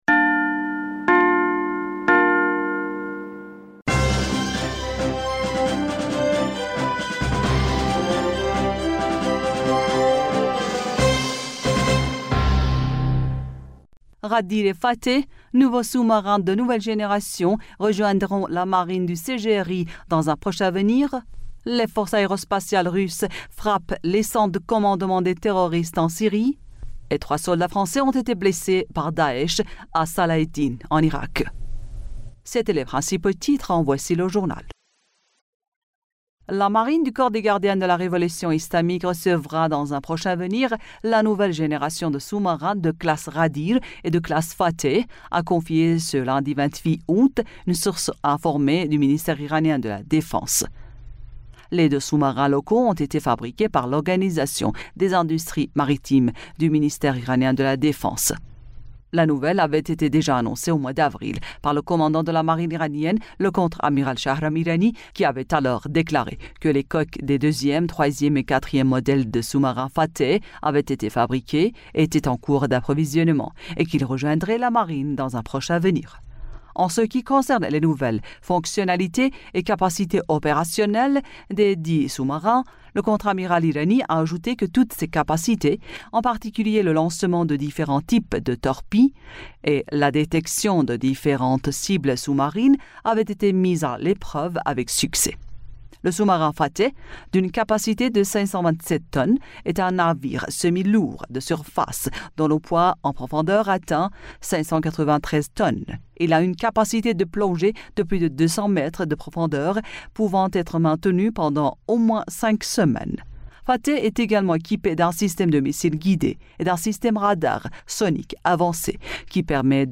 Bulletin d'information du 29 Aout 2023